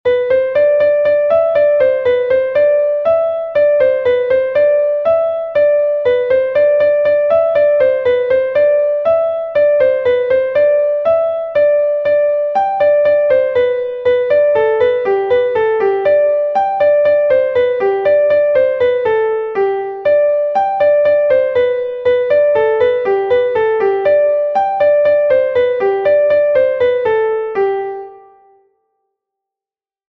Laridé Koun III est un Laridé de Bretagne enregistré 1 fois par Koun